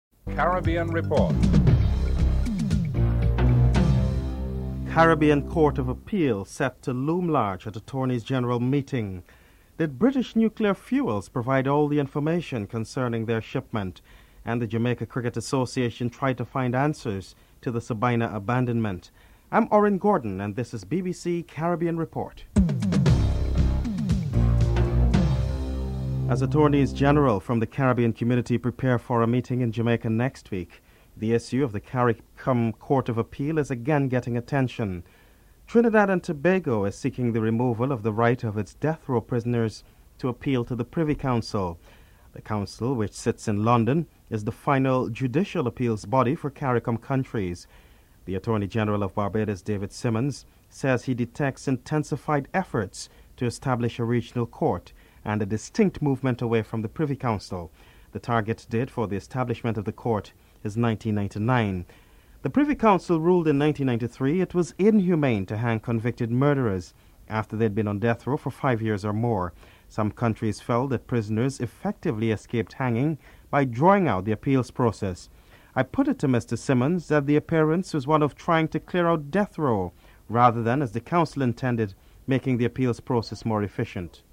The Attorney Generals from CARICOM prepare for a meeting in Jamaica and the issue of the Caribbean Court of Appeal looms large. Barbados Attorney General David Simmons speaks on the intensified efforts to establish the regional court.